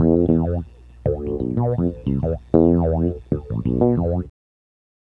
Bass Lick 35-08.wav